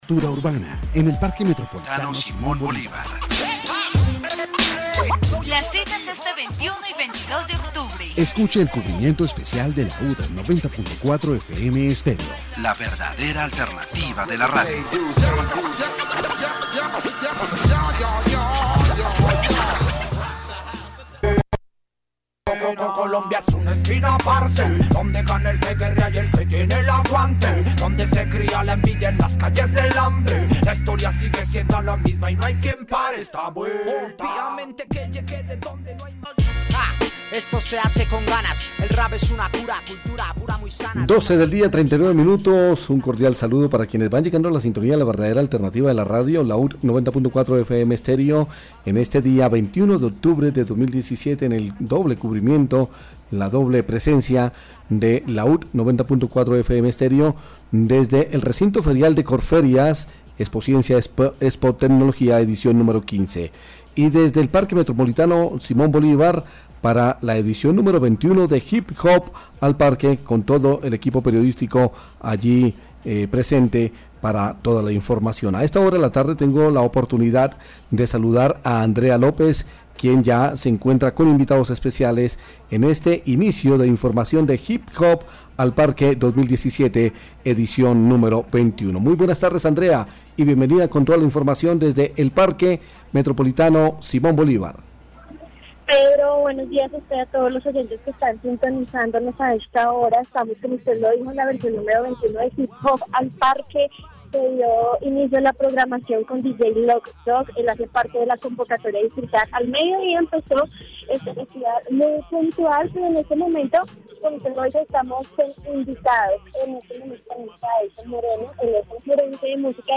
Programa, Cundinamarca, Bogotá
Programa de radio , Bogotá D.C (Region, Colombia) -- Grabaciones sonoras
Entrevistas